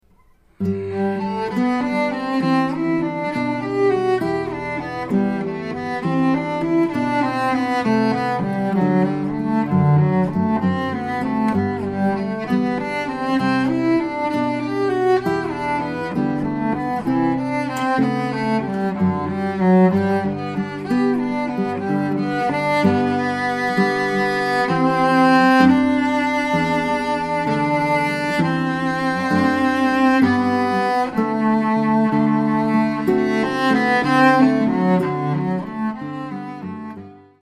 Guitar and Cello
I provide a harmonic background using my classical guitar.